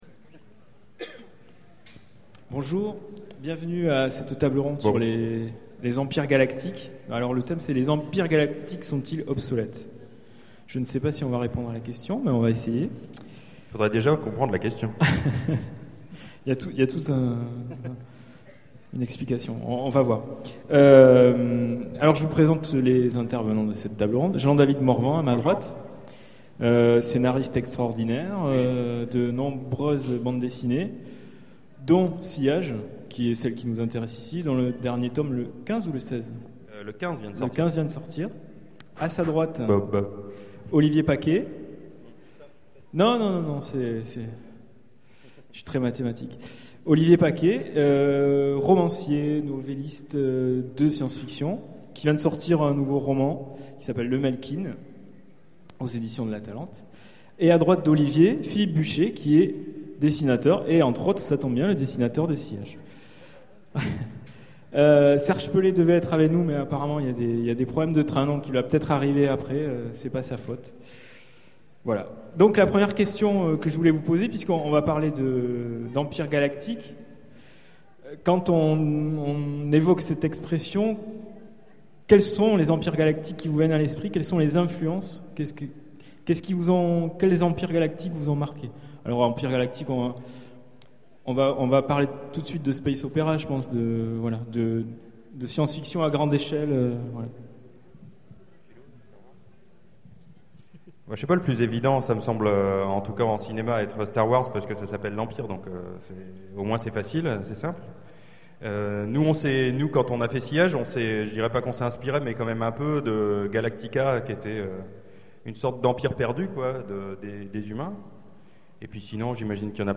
Utopiales 12 : Conférence Les empires galactiques sont-ils obsolètes ?